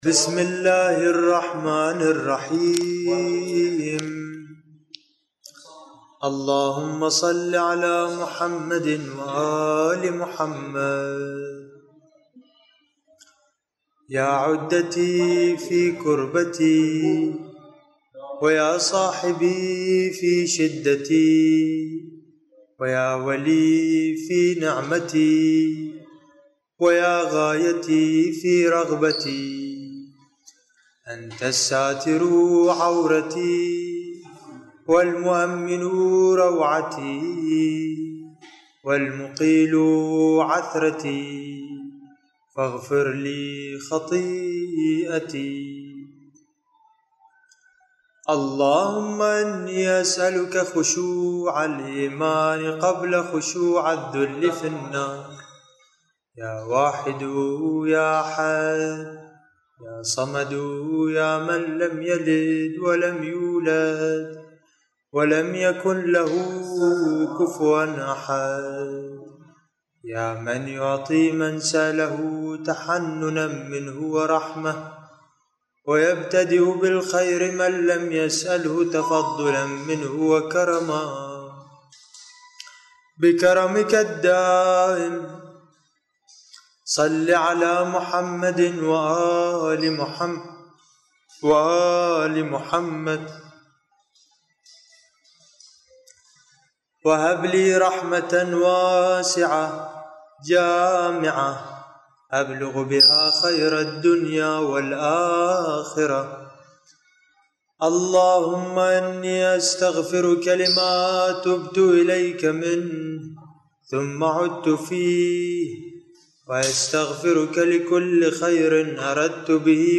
دعاء
الرادود